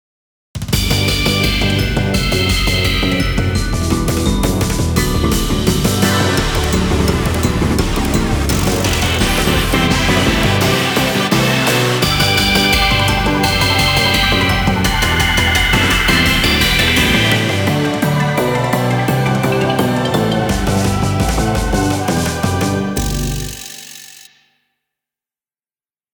ダークが色濃いシリーズです。
inst